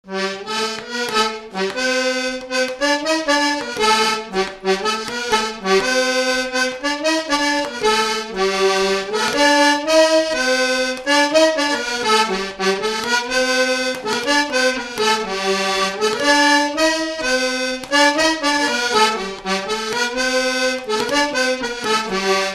Mémoires et Patrimoines vivants - RaddO est une base de données d'archives iconographiques et sonores.
Résumé instrumental
circonstance : quête calendaire
Pièce musicale inédite